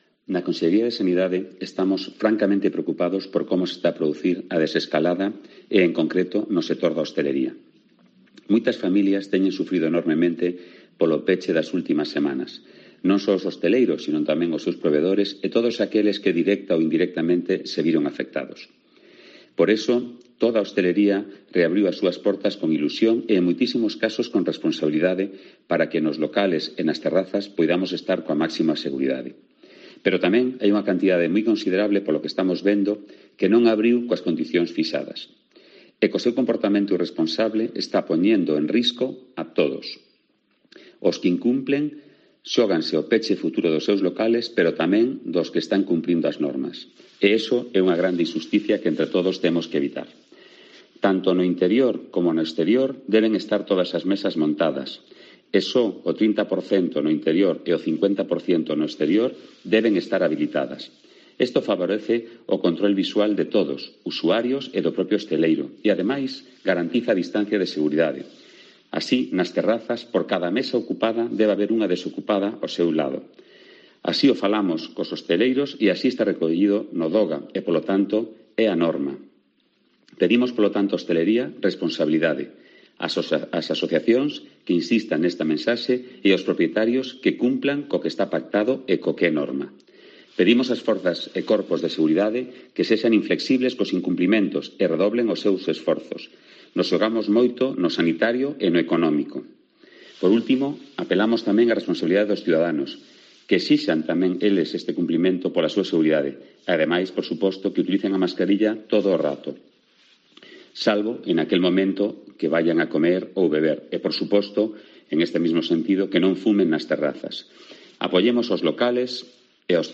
Declaración completa del Conselleiro de Sanidade, Julio García Comesaña